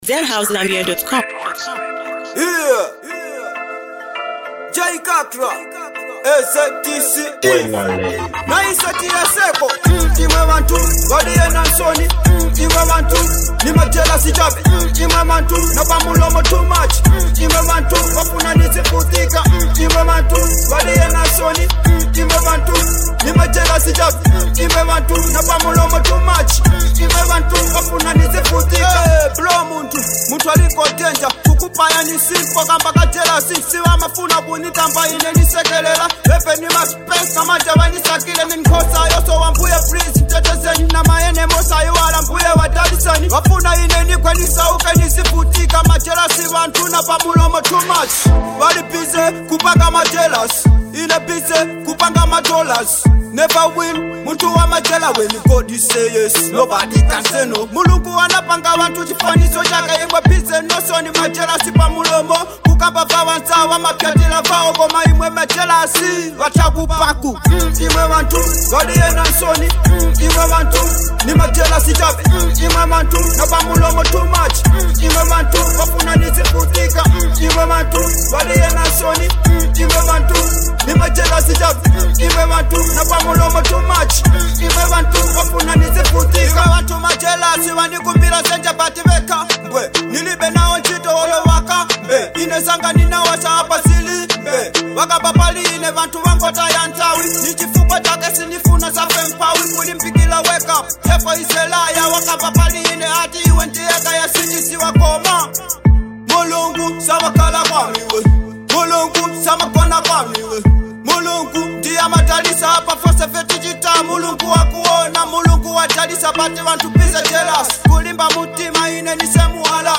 ” a powerful anthem dedicated to the people